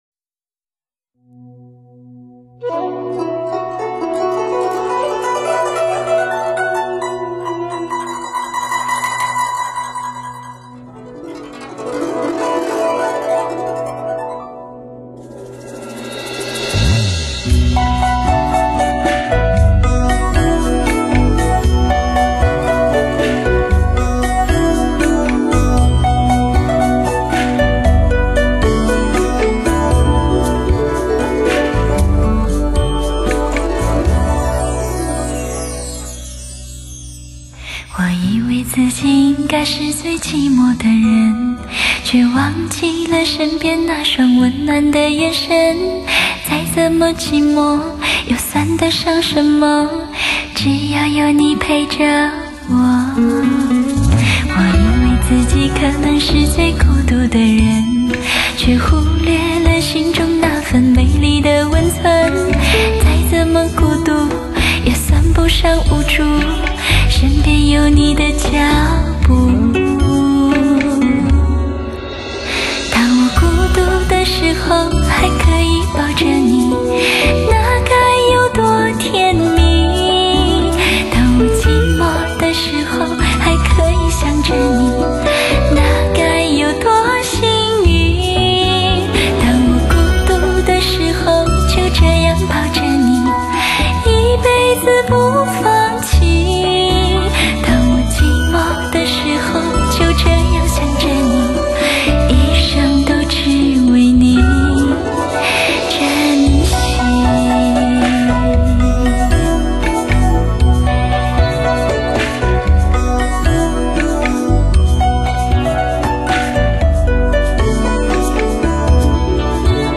悠悠憨厚的至真歌声 无意中让你品味人生真缔
黑胶CD兼容黑胶的高保真和CD的低噪音 开创靓声新纪元
可在CD机上和汽车音响上听到LP黑胶密文唱片的仿真音质，感受那种愉悦的发烧韵味